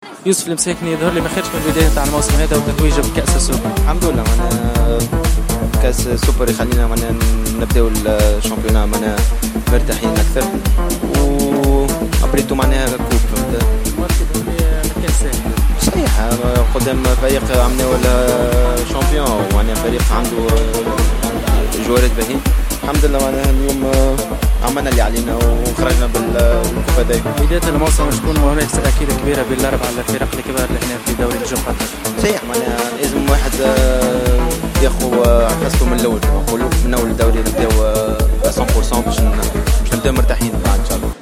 و تحدث التونسي يوسف المساكني إثر اللقاء مع مراسلنا في الدوحة